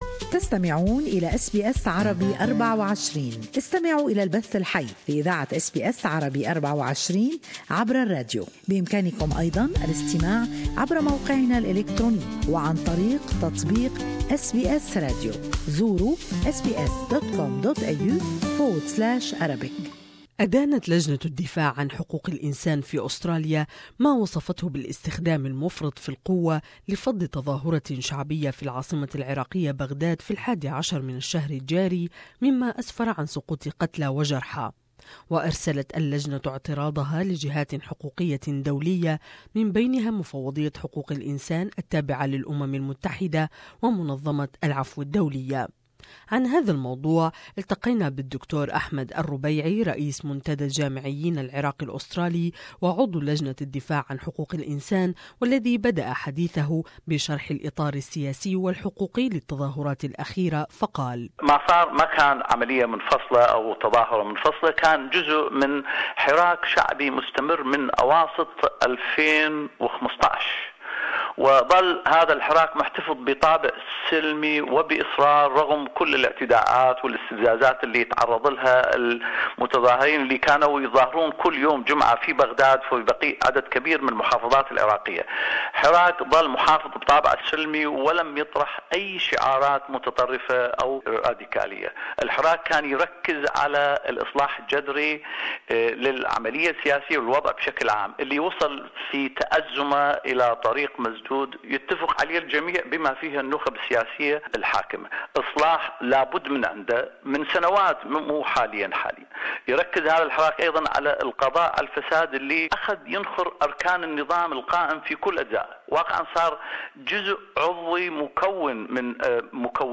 The Committee to defend Human Rights, has condemned the recent attacks on peaceful protesters in the capital of Iraq on the 11th of February In its letter to major international groups, the committee called upon the Iraqi Government and Parliament to condemn this violence and to investigate the crime scene in Baghdad and bring the perpetrator to justice. More in this interview